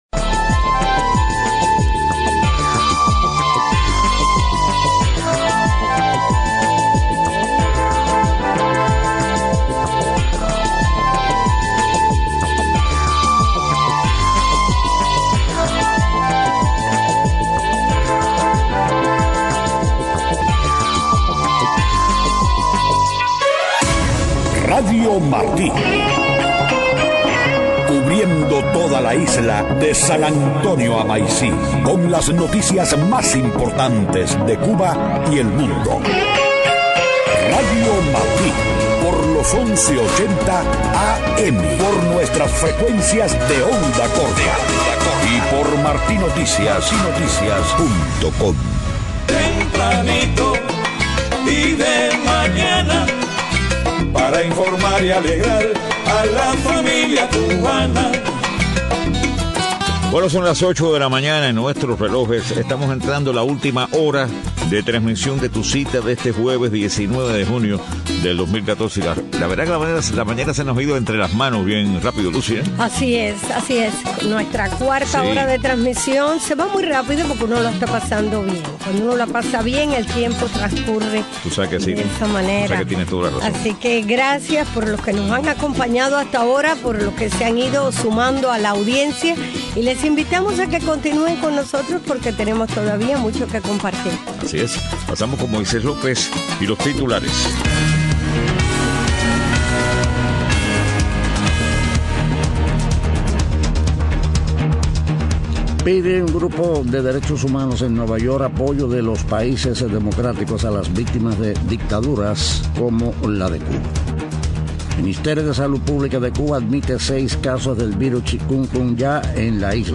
Estado del tiempo. Deportes.